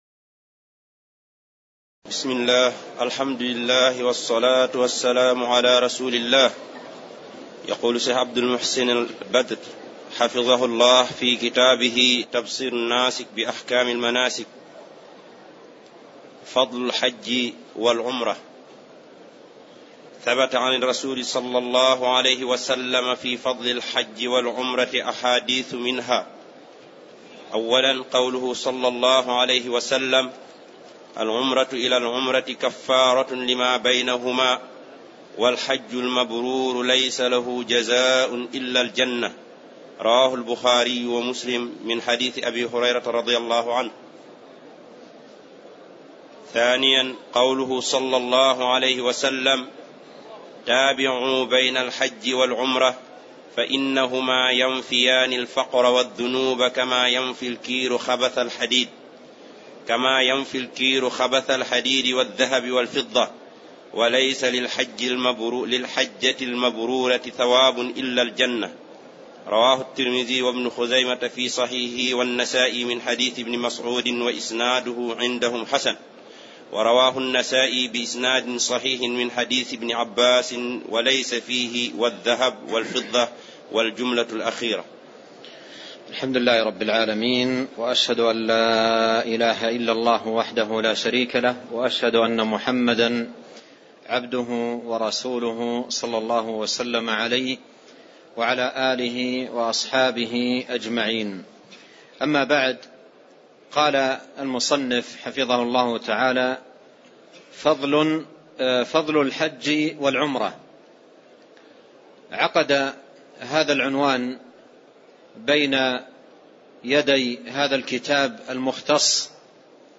تاريخ النشر ٢١ ذو القعدة ١٤٣٠ المكان: المسجد النبوي الشيخ